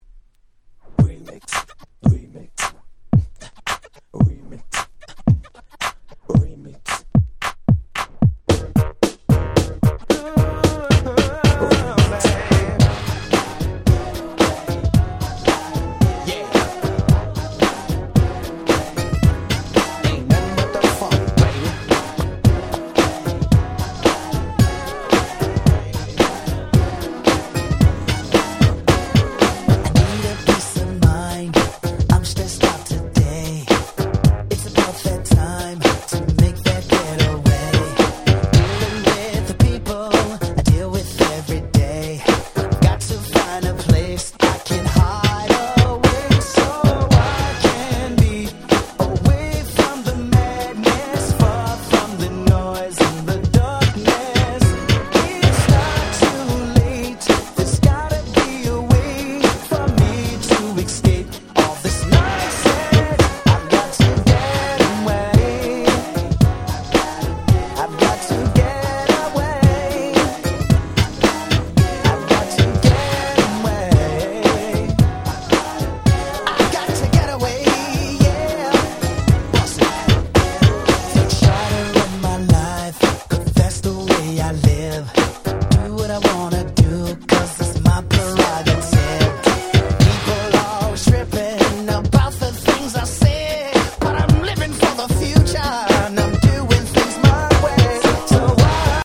New Jack Swing